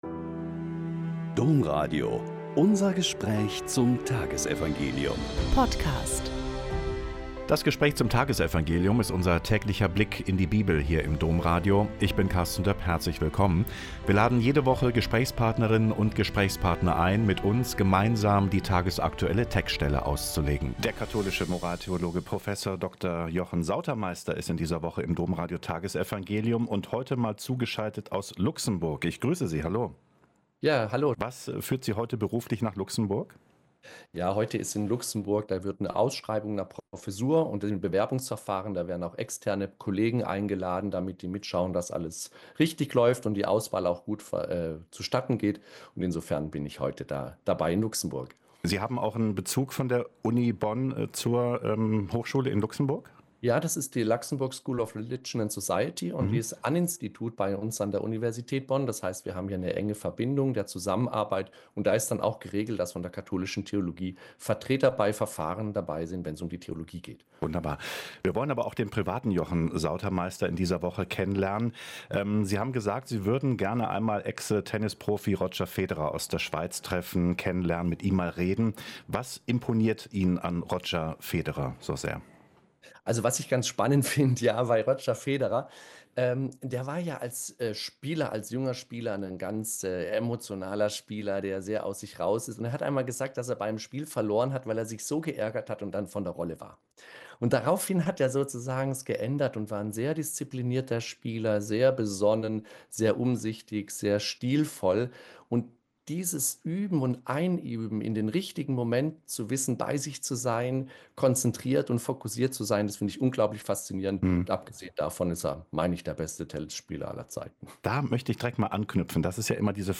Mk 12,28b-34 - Gespräch